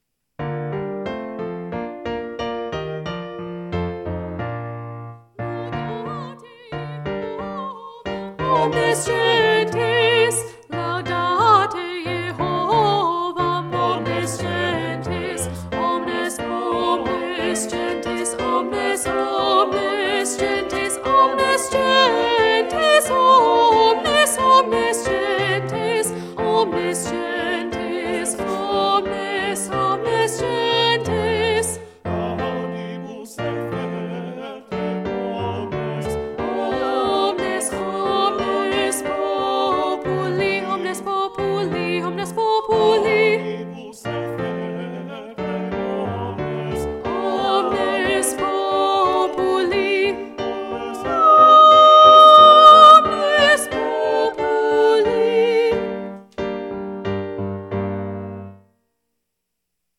Allegro - Alto
Laudate+Jehovam+1+Allegro+-+Alto.mp3